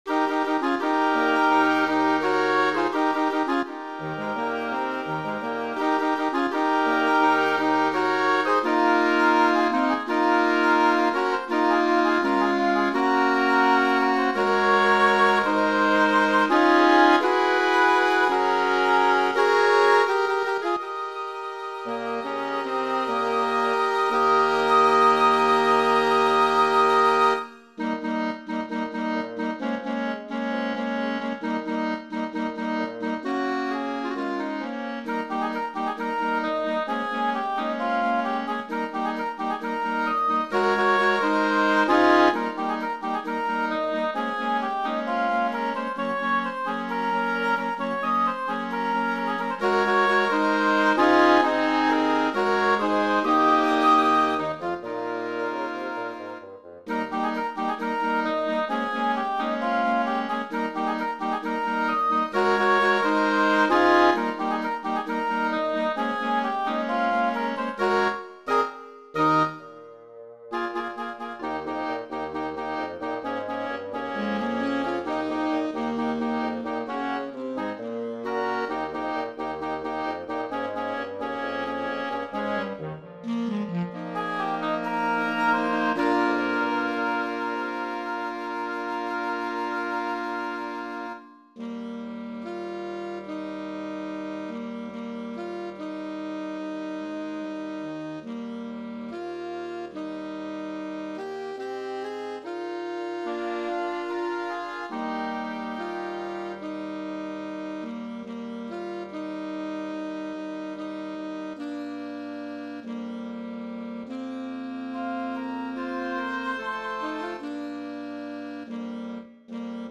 Voicing: 5 Woodwinds